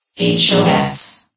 sound / vox_fem / hos.ogg
CitadelStationBot df15bbe0f0 [MIRROR] New & Fixed AI VOX Sound Files ( #6003 ) ...